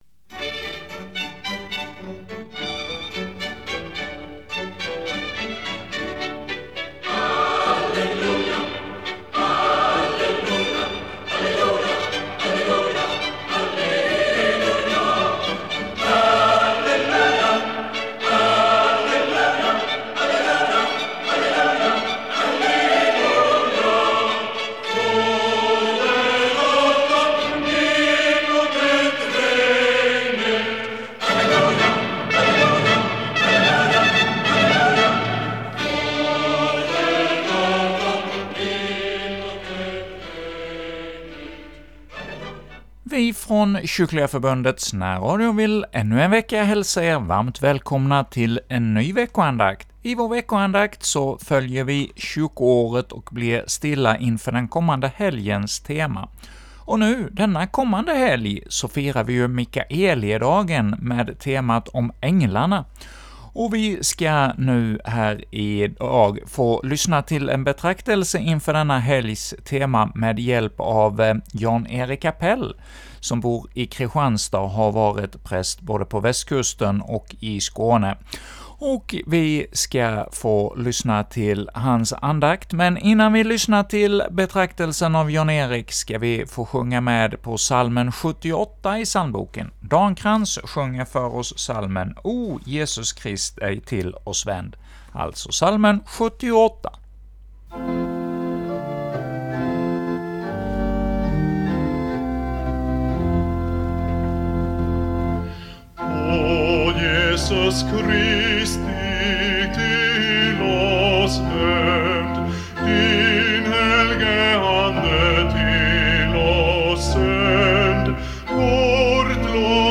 Psalmer: 78, 379:3-5
andakt inför Mikaelidagen